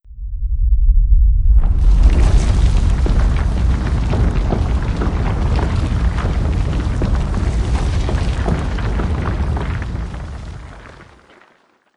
earthquake_cracking.wav